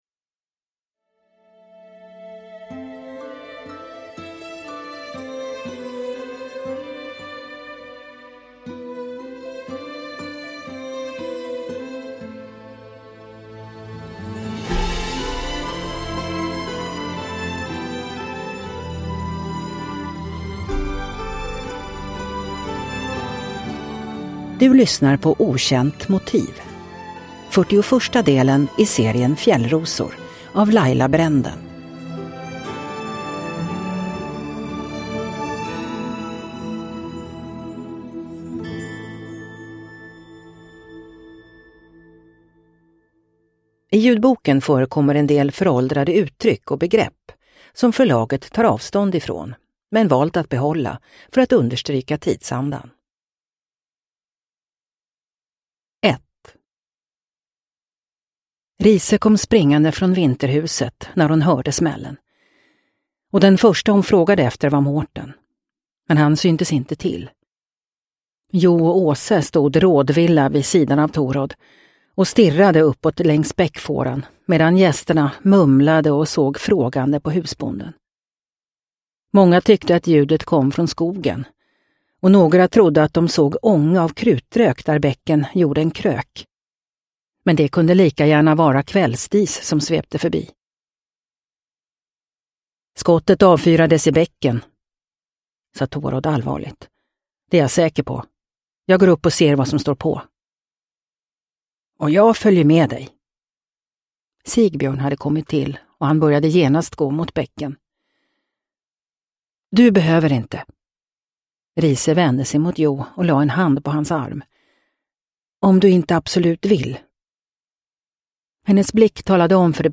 Okänt motiv – Ljudbok – Laddas ner